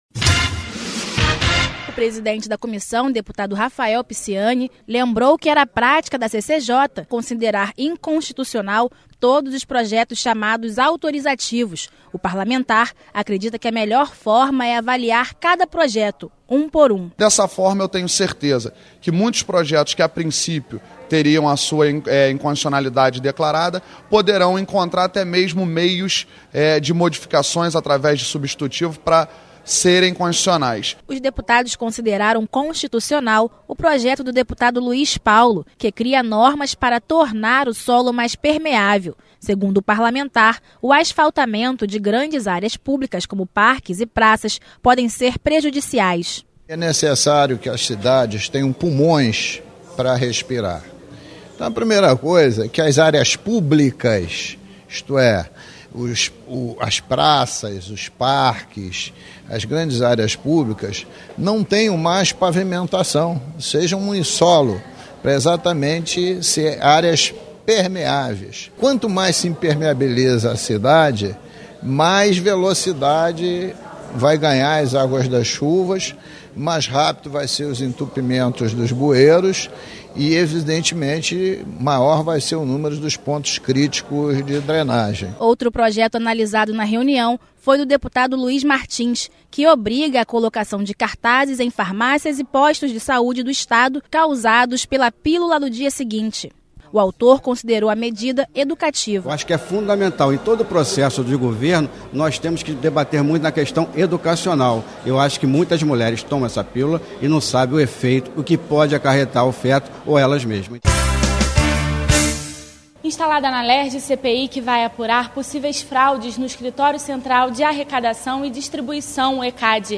Programa diário com reportagens, entrevistas e prestação de serviços
Boletim da Rádio Assembleia